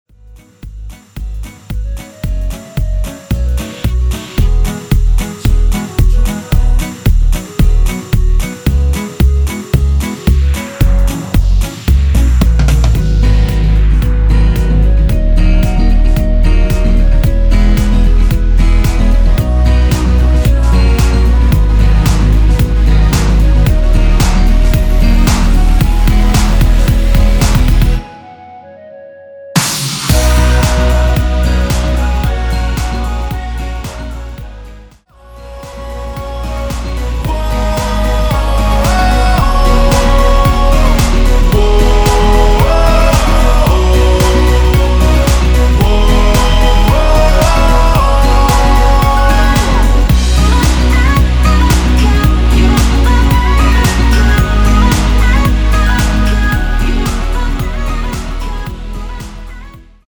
원키에서(-1)내린 멜로디와 코러스 포함된 MR입니다.
앞부분30초, 뒷부분30초씩 편집해서 올려 드리고 있습니다.
중간에 음이 끈어지고 다시 나오는 이유는